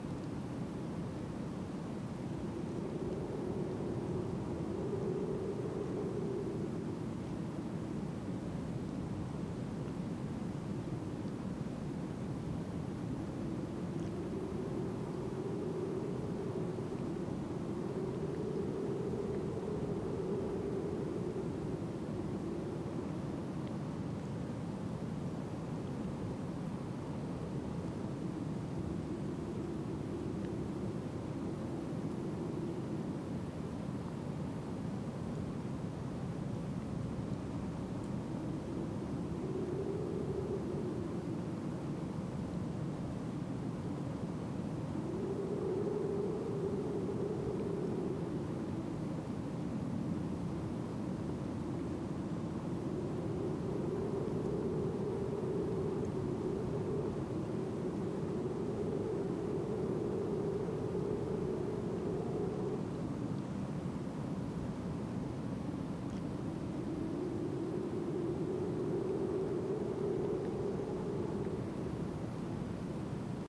base-wind-aquilo.ogg